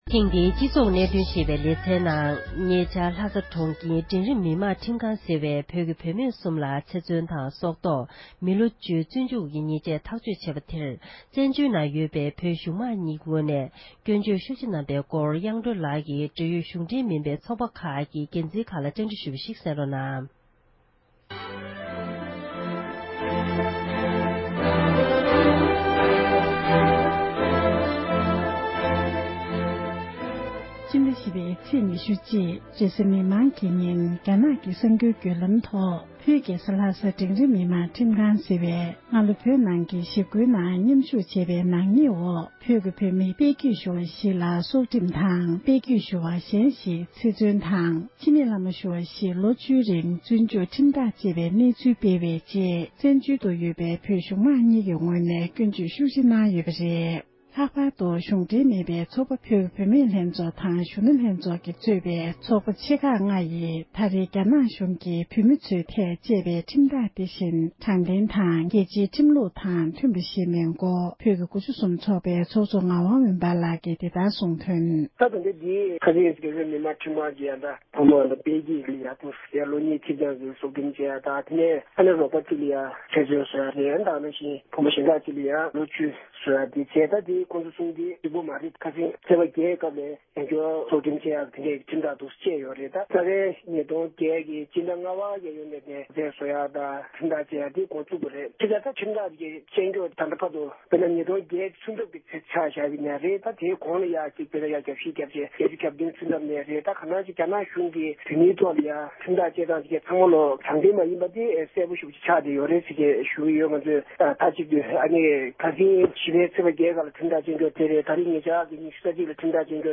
འབྲེལ་ཡོད་གཞུང་འབྲེལ་མིན་པའི་ཚོགས་པ་ཆེ་ཁག་གི་འགན་འཛིན་ཁག་ལ་བཅའ་འདྲི་ཞུས་པར་གསན་རོགས་ཞུ